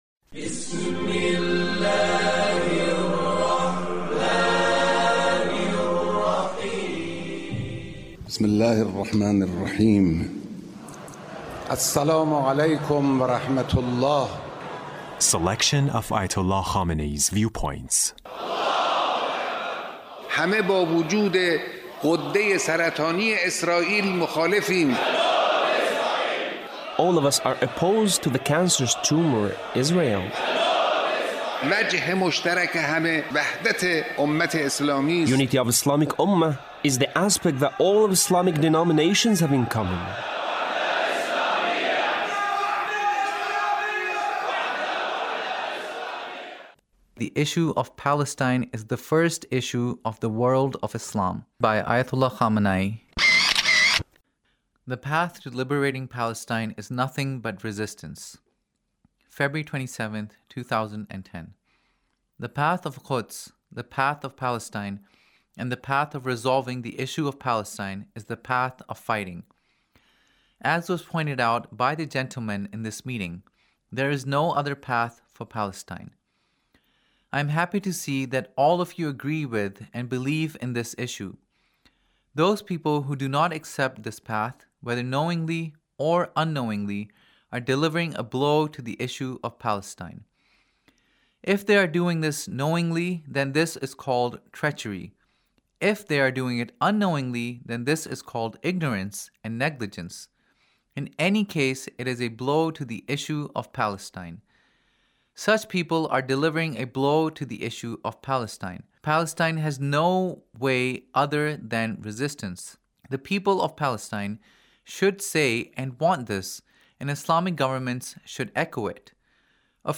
Leader's Speech (1872)